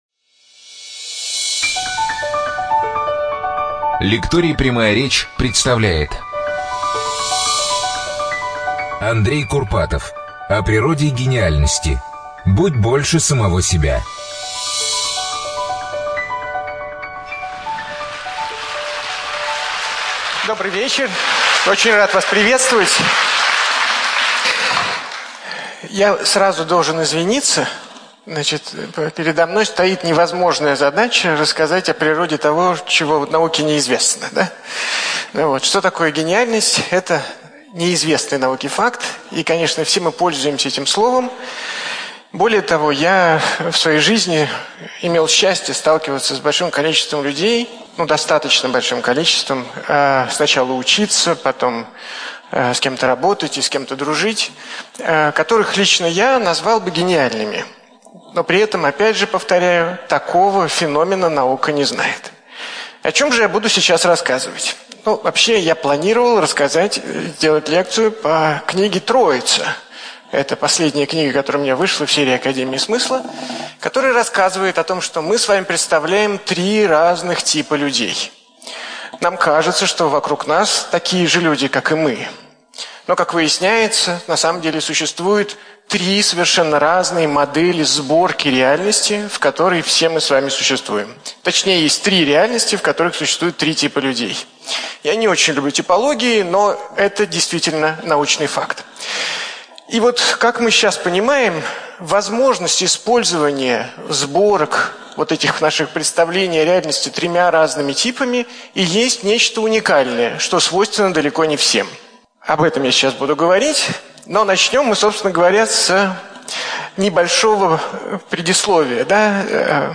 ЧитаетАвтор
Студия звукозаписиЛекторий "Прямая речь"